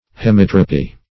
Hemitropy \He*mit"ro*py\, n.
hemitropy.mp3